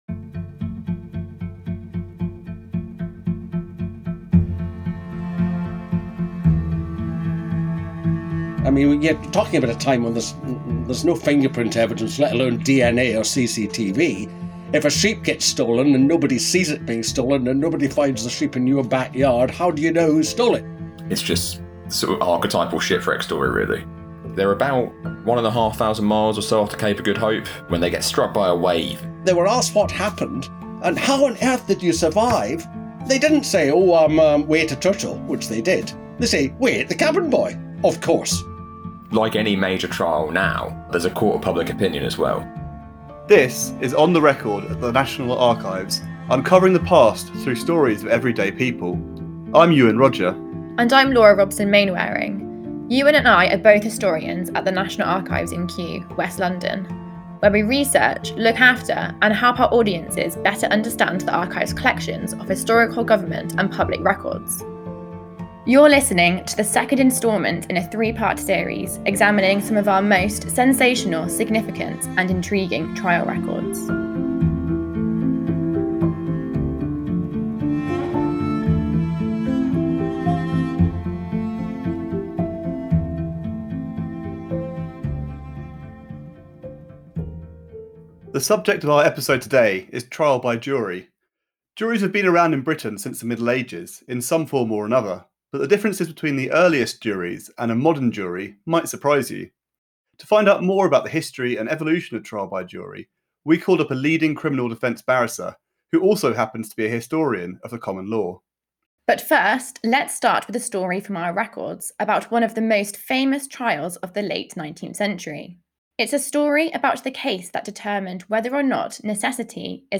Then a barrister and historian explains the origins of trial by jury, how juries have evolved over 600 years, and why it’s so important to understand their history.